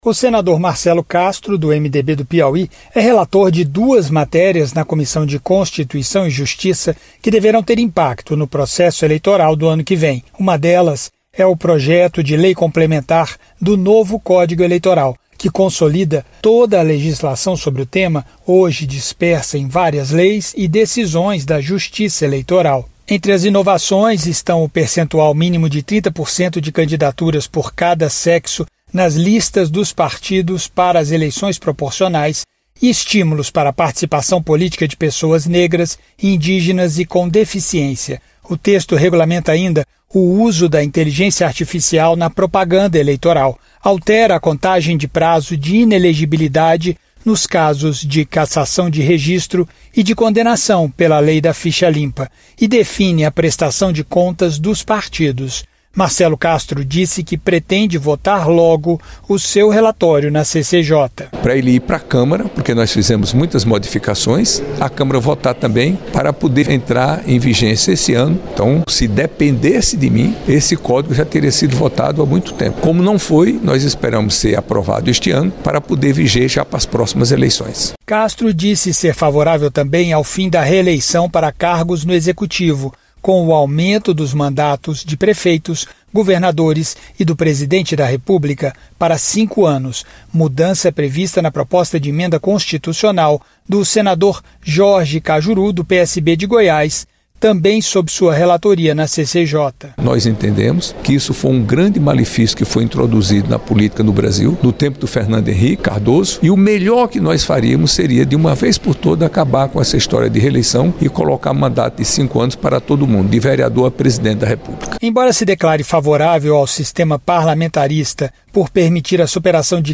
2. Notícias